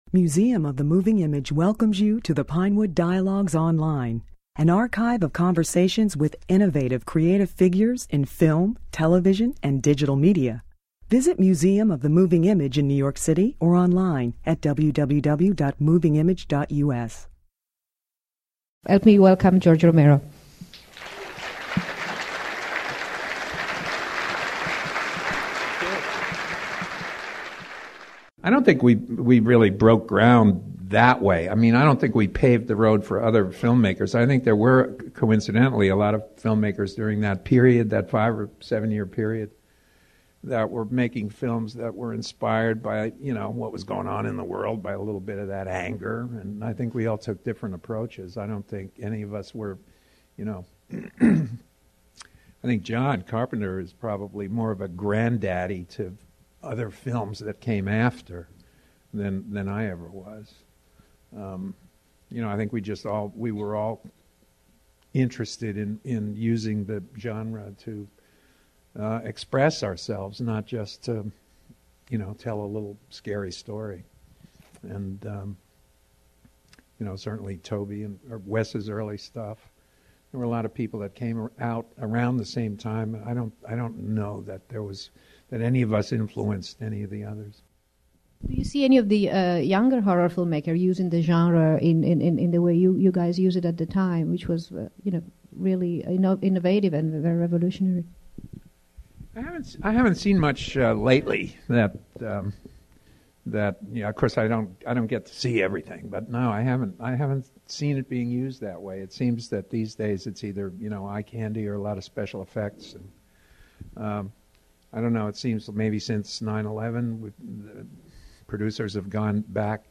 He spoke on the opening day of a Moving Image retrospective of his films.